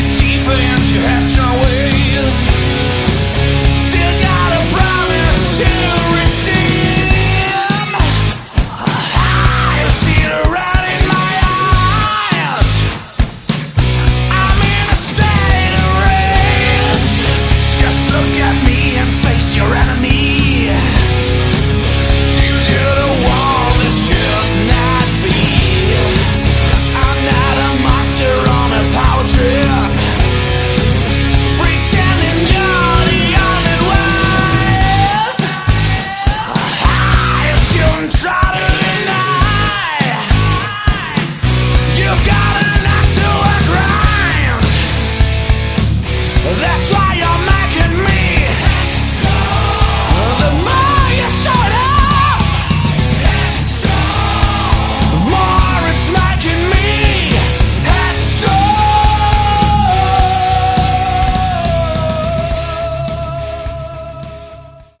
Category: Hard Rock/ Melodic Metal
Excellent vocals and razor sharp guitars.